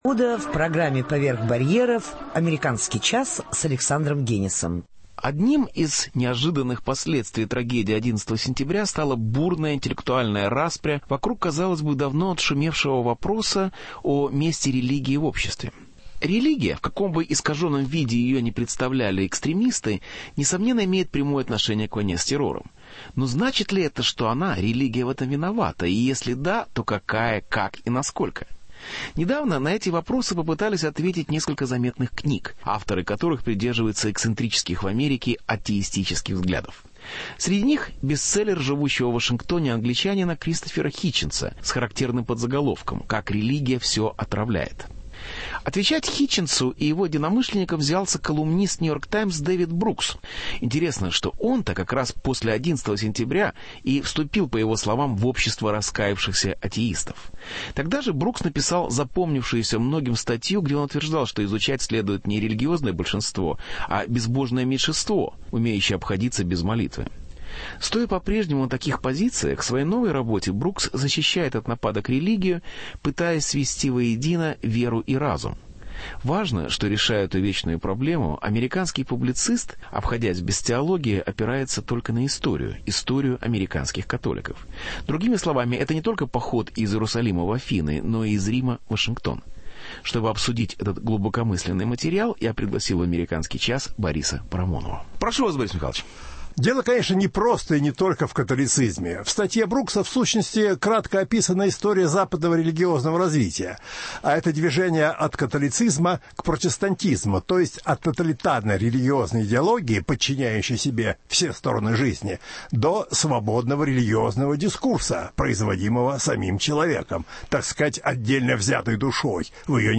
Религия в 21-м веке: Дискуссия с Борисом Парамоновым